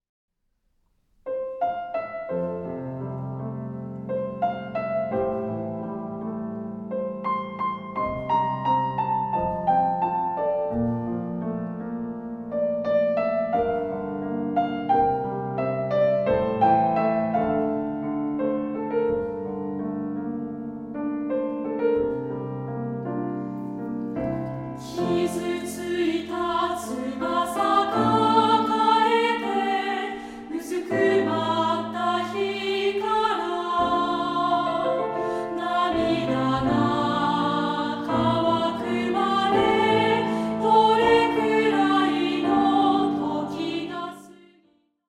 同声2部合唱／伴奏：ピアノ